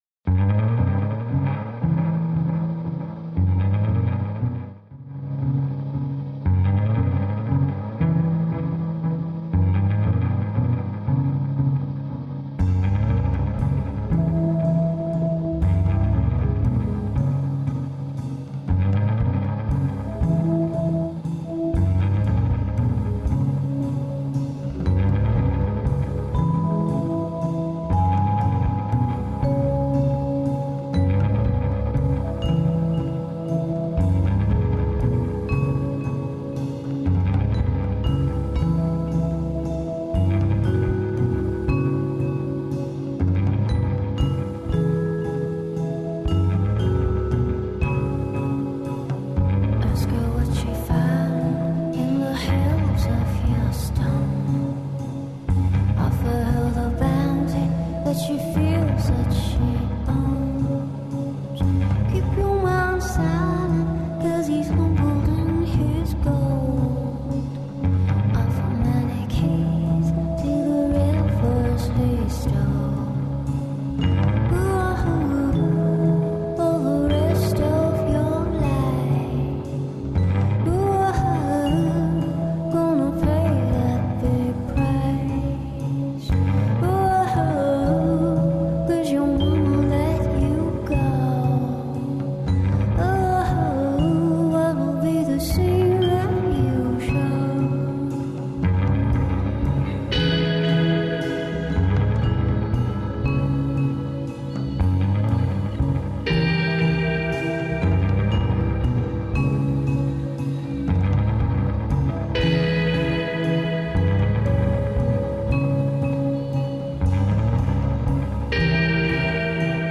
Гости су и бенд Кома, инструментални састава који је почео са радом 2009. године, и бендови Аутопарк и Нежни Далибор.
Емисија из домена популарне културе.